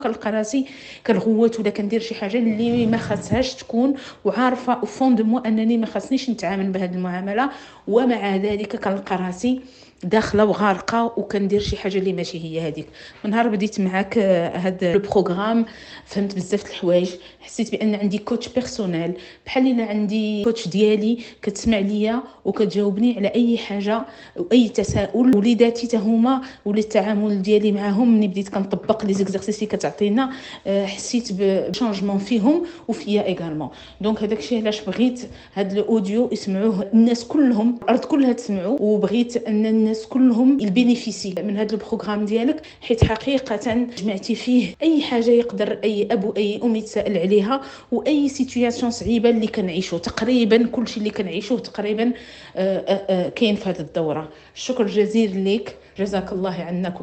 ماذا قال من شارك في الدورة